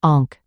This glued sound says: /ŏnk/, /ŏnk/, /ŏnk/, bonk.